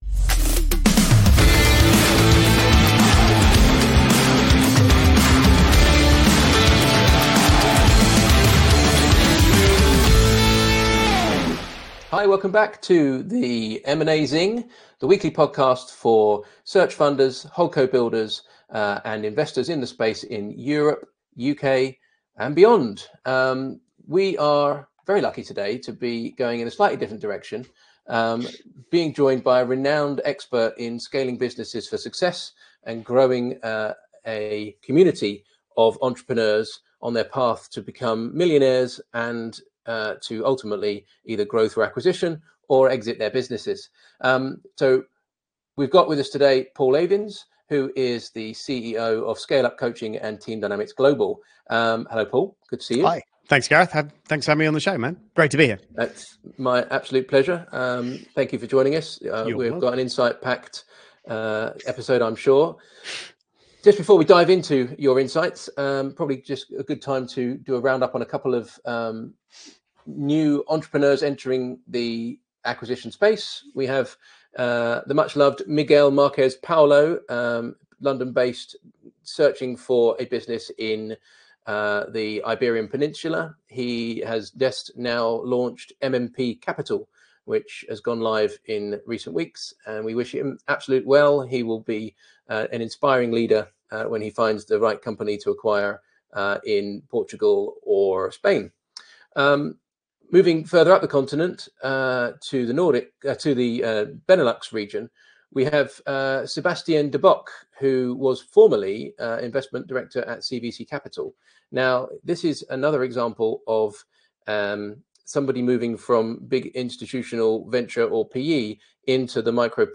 In this rapid-fire conversation we cover: